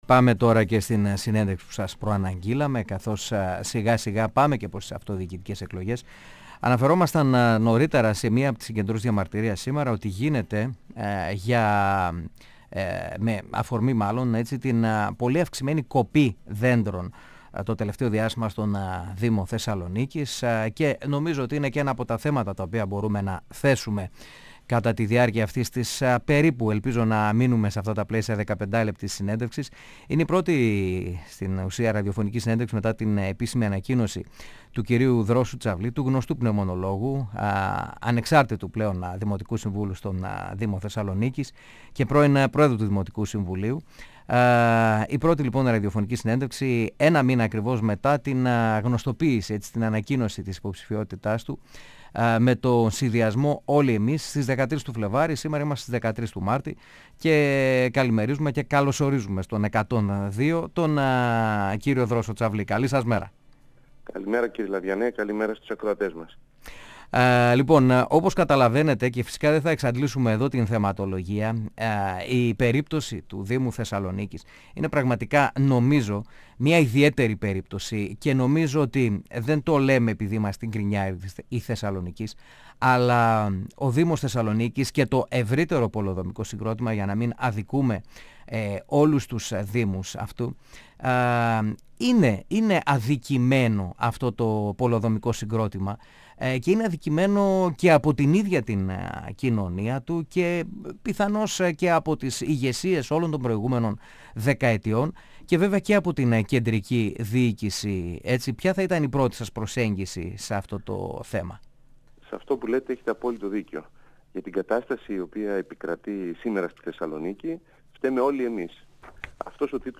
Για συγκεντρωτισμό κατηγόρησε τον δήμαρχο Θεσσαλονίκης, ο πρώην πρόεδρος του Δημοτικού Συμβουλίου, πνευμονολόγος Δρόσος Τσαβλής, στην πρώτη ραδιοφωνική συνέντευξή του ως υποψήφιος δήμαρχος, επικεφαλής του συνδυασμού Όλοι Εμείς.
Συνεντεύξεις